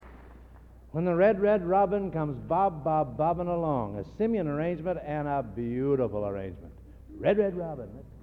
Collection: Gala Concert, 1961
Genre: | Type: Director intros, emceeing